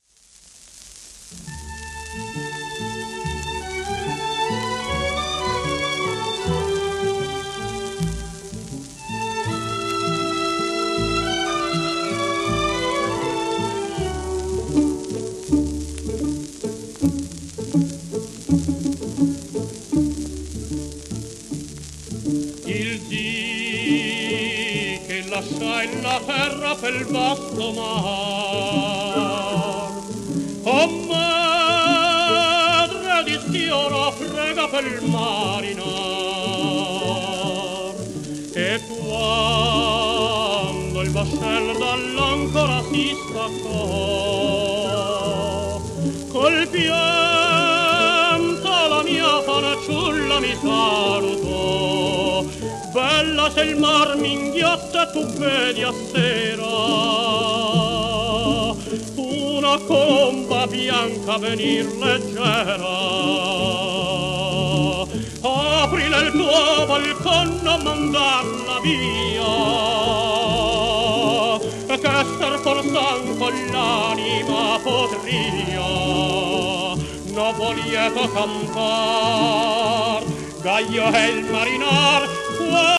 w/オーケストラ
シェルマン アートワークスのSPレコード